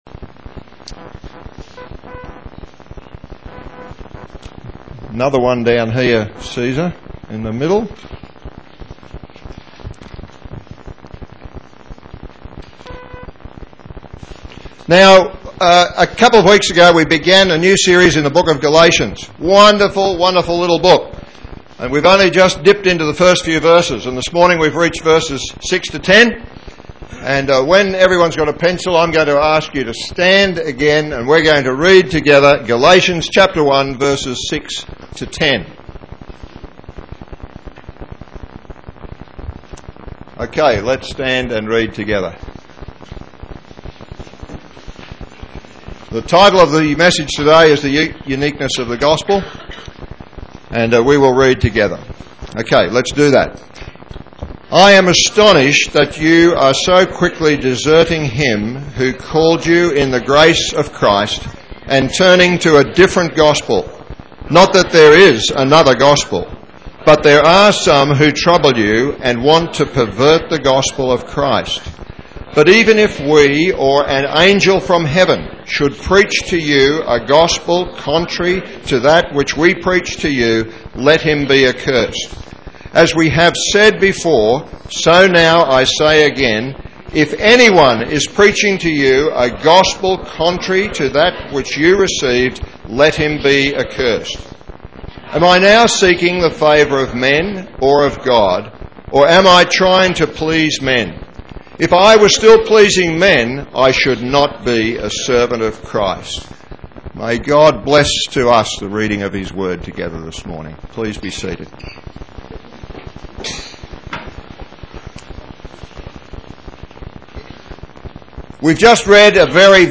Galatians 1:6-10 Listen to the sermon here.